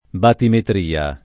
batimetria [ batimetr & a ]